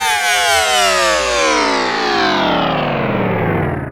SCIFI_Down_10_mono.wav